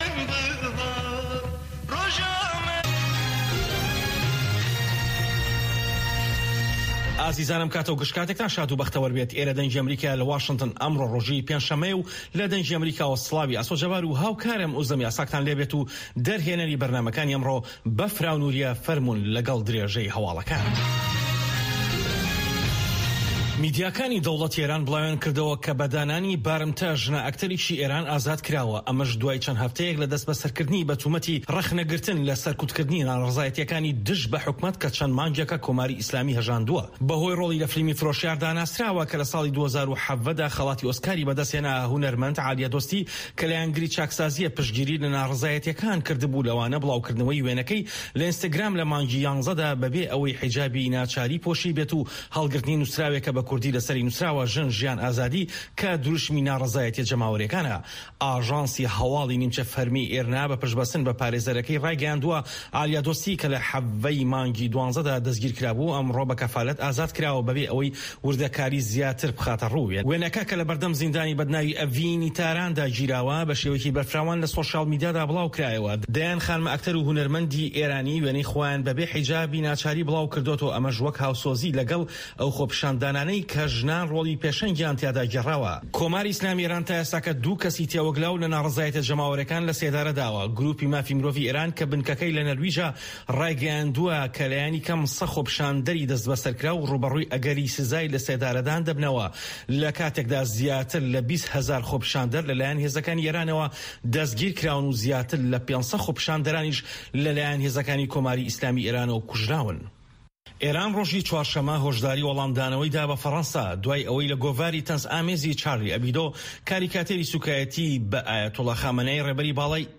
Nûçeyên Cîhanê 2